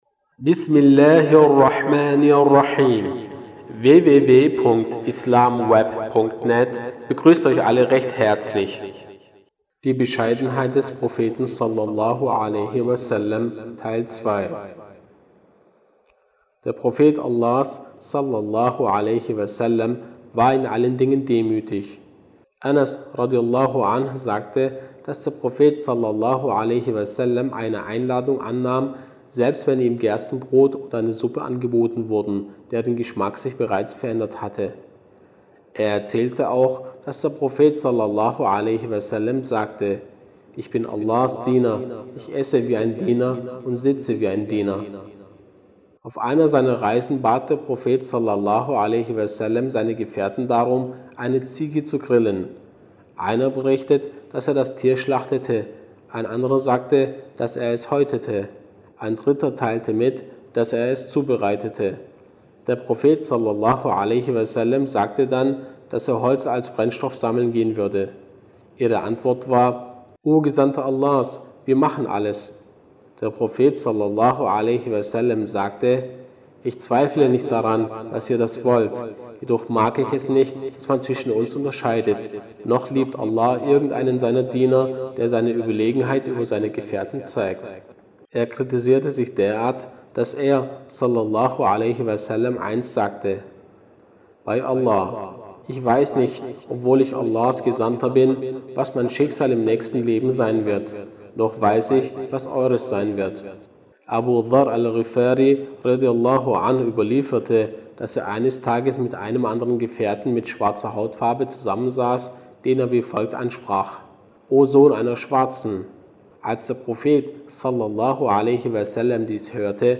Lesungen